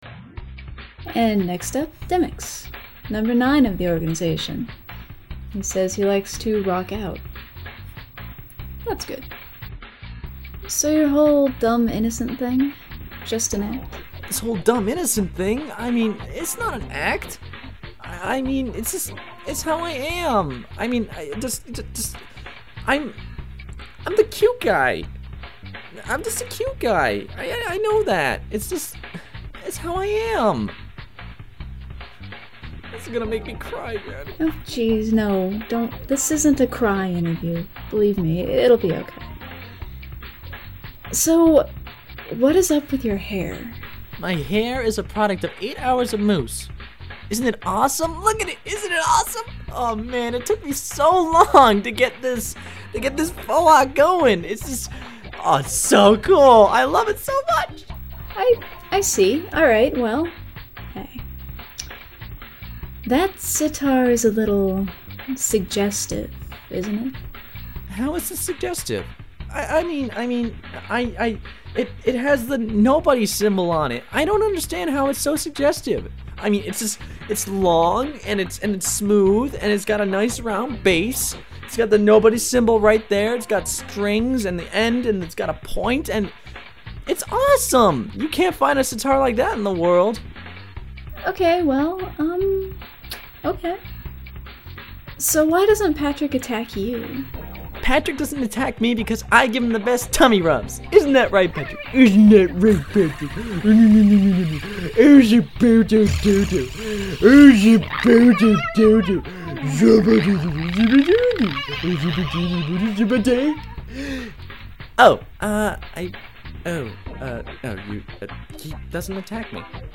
Character Interviews
09DemyxInterview.mp3